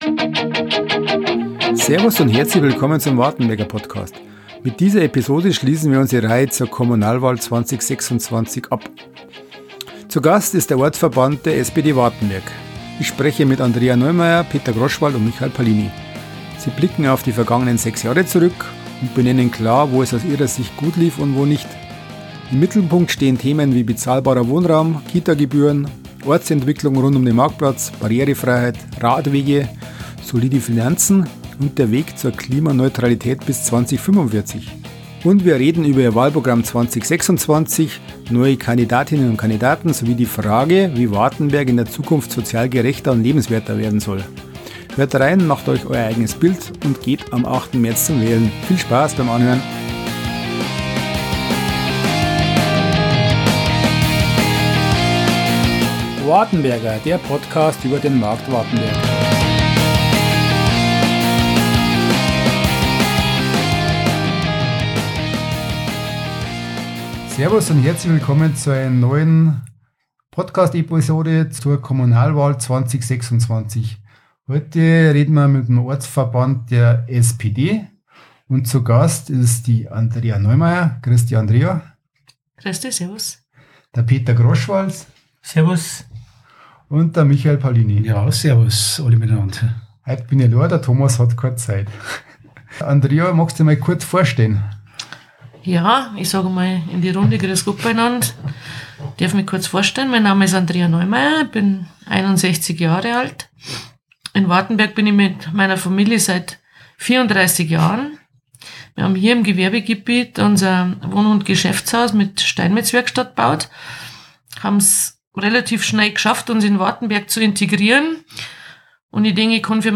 In der abschließenden Folge unserer Reihe zur Kommunalwahl 2026 spricht der Wartenberger Podcast mit dem Ortsverband der SPD.